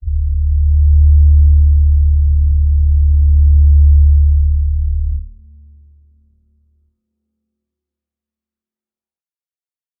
G_Crystal-D2-pp.wav